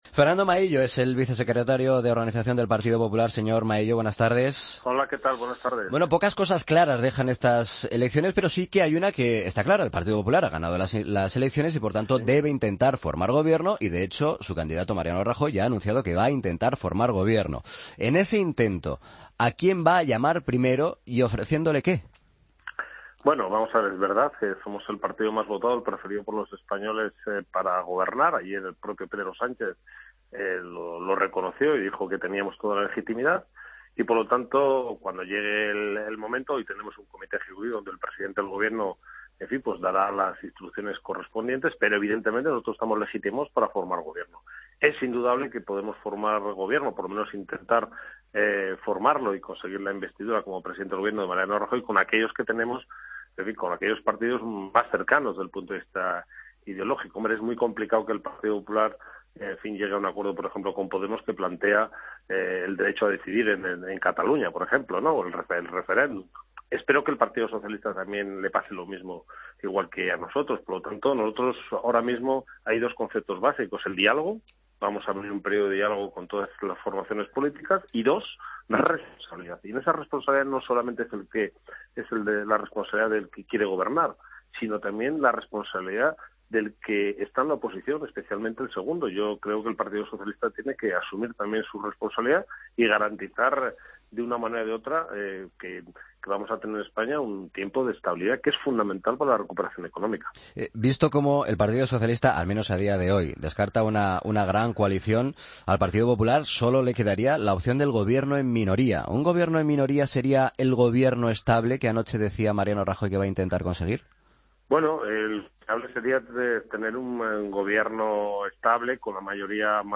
Escucha la entrevista a Fernando Maillo, vicesecretario de Organización del PP